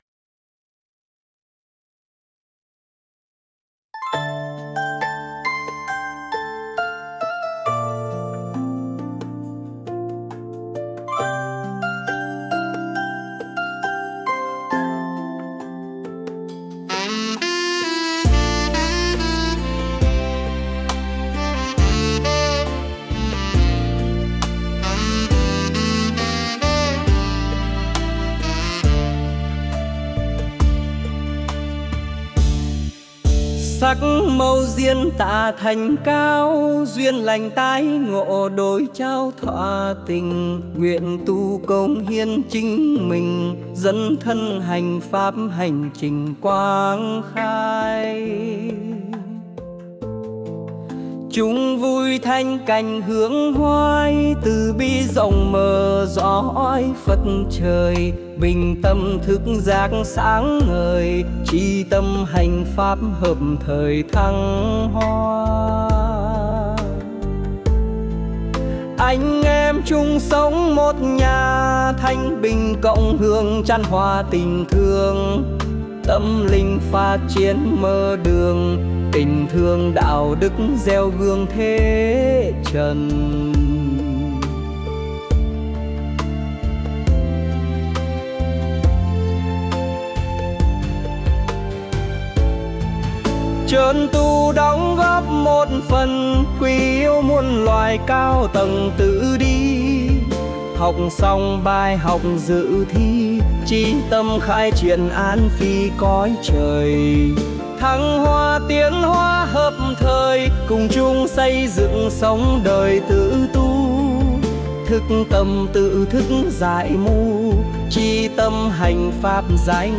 170-Duyen-lanh-01-nam.mp3